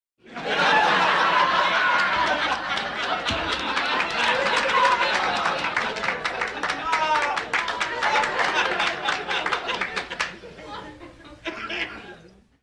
laugh.mp3